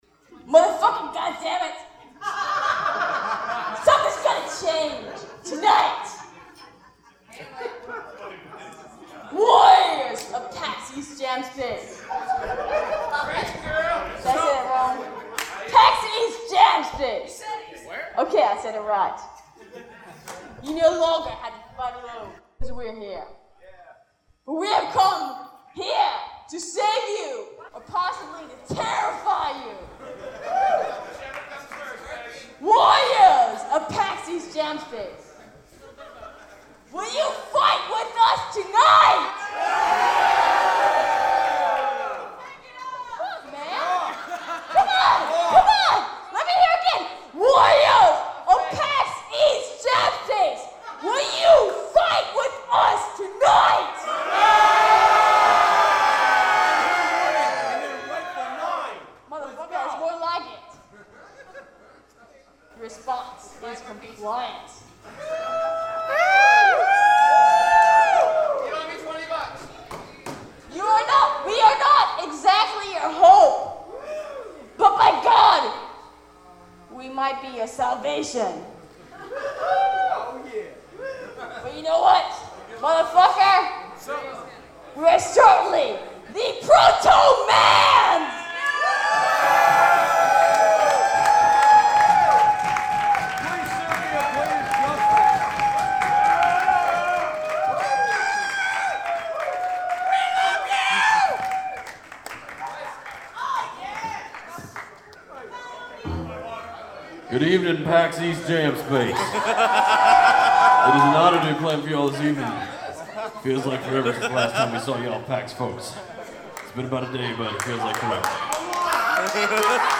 Plus we brought our recorders with us and captured a bunch of exclusive live recordings including The Protomans – the Protomen cover band, and interviewed the actual Protomen after their double’s debut.
TheProtomansLiveatPAXEast.mp3